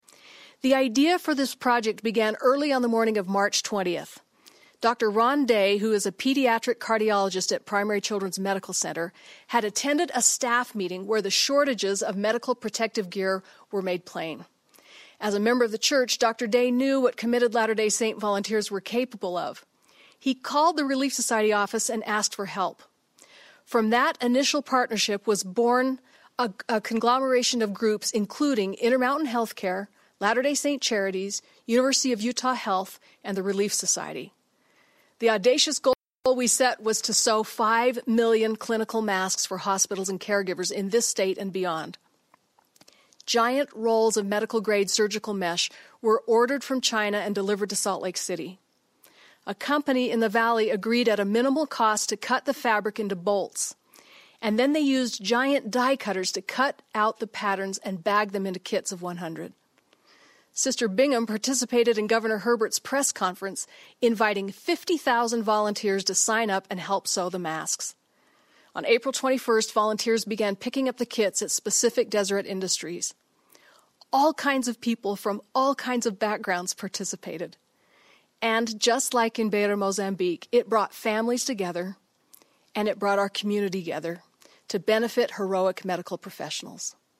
Attached is a sound clip from a BYU Women's Conference held this week. This clip is from a presentation given on Friday talking about how it came together here: